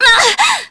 Miruru_L-Vox_Damage_jp_02.wav